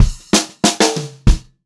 96POPFILL1-L.wav